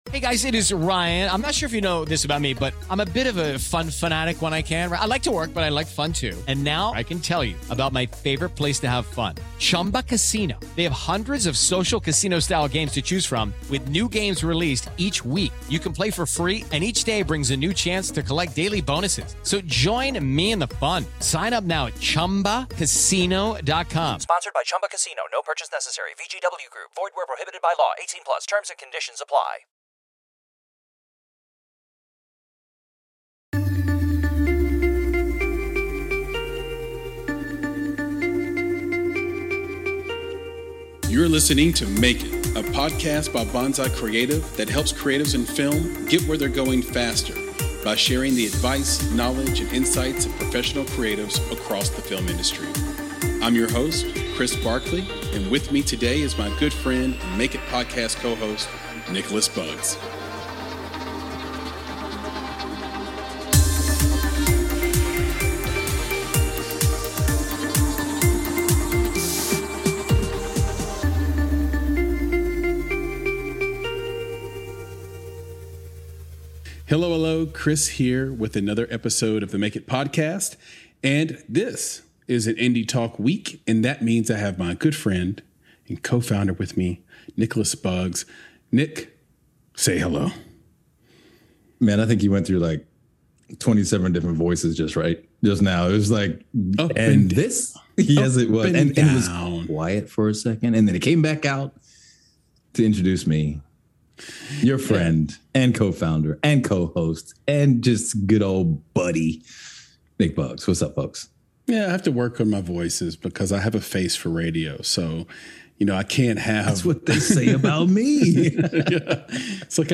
202 - How Theaters Can Thrive After COVID-19 & a PSA on Marvel's Shang-Chi (Indie Talk